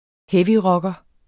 Udtale [ ˈhεviˌʁʌgʌ ]